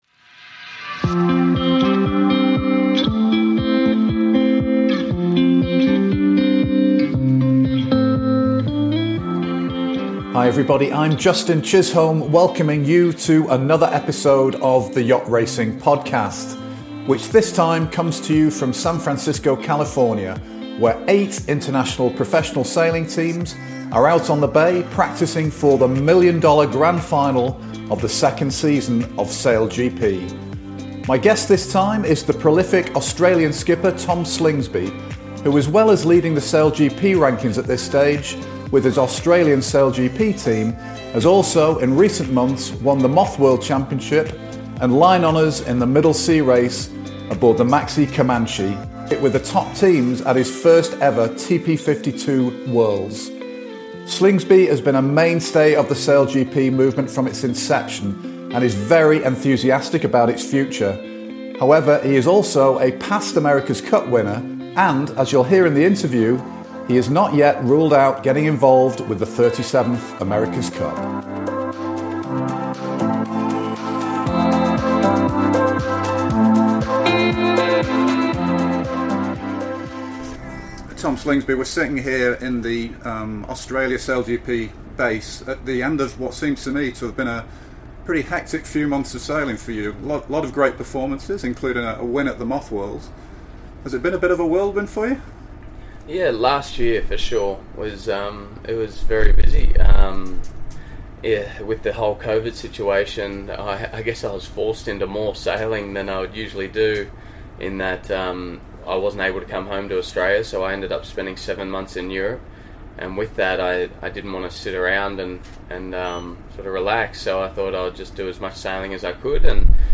Yacht Racing Life Podcast Tom Slingsby Mar 23 2022 | 00:34:22 1x 00:00 / 00:34:22 Subscribe Share Apple Podcasts Spotify Overcast RSS Feed Share Link Embed